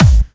VEC3 Clubby Kicks
VEC3 Bassdrums Clubby 003.wav